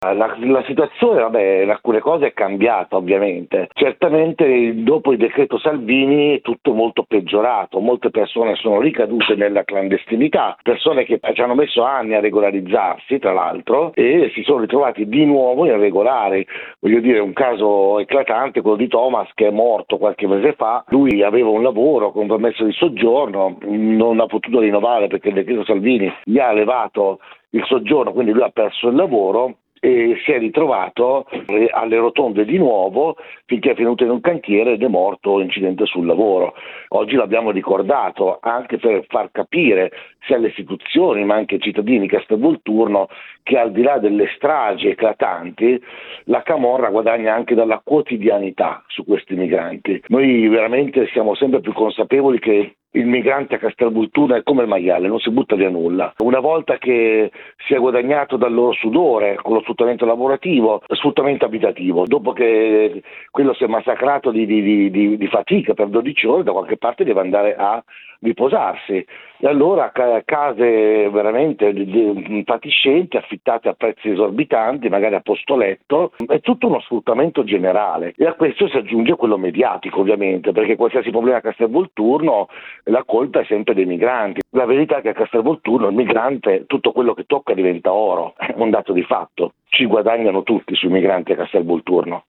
Oggi come ogni anno il ricordo e le commemorazioni a cui hanno partecipato migranti , associazioni e sindaci della zona. Noi abbiamo cercato di capire  come vivono oggi i migranti a Castel Volturno, a 13 anni da quella strage.